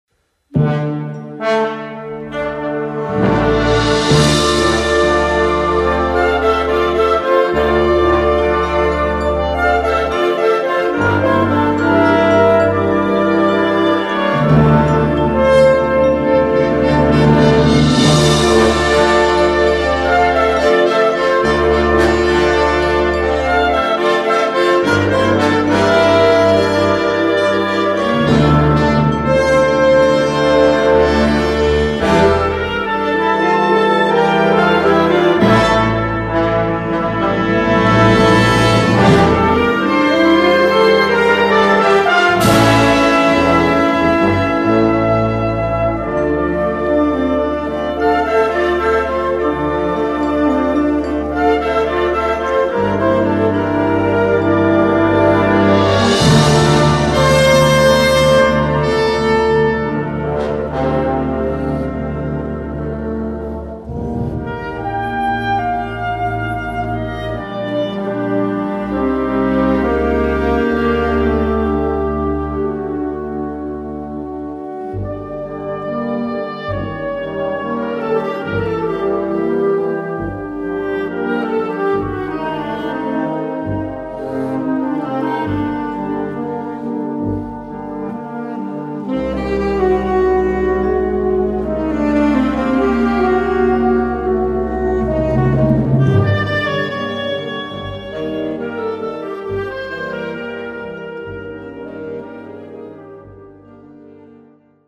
Sheet music from the movies for Brass Band